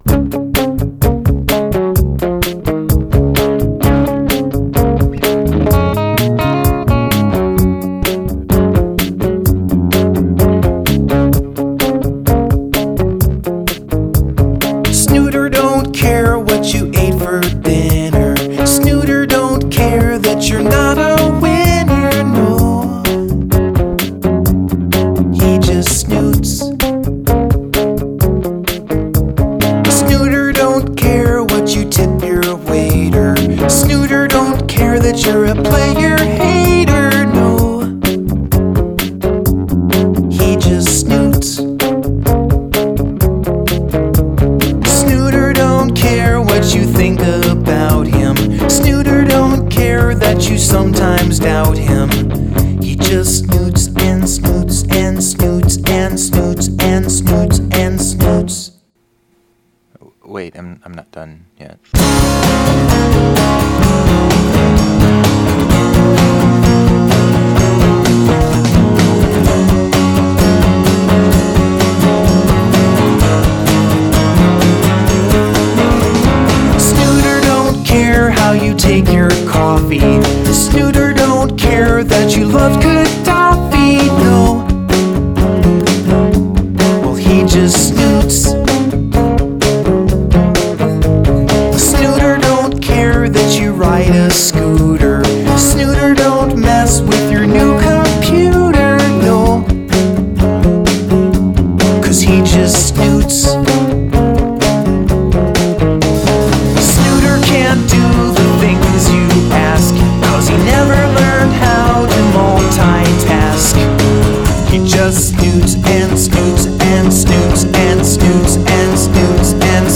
Started out 80s pop styling.
The beginning is really catchy.
I also like the vocal/guitar twinning on the chorus.
It's a little poppy for my tastes.
I love the vocals and the riff.